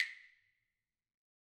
Claves1_Hit_v3_rr2_Sum.wav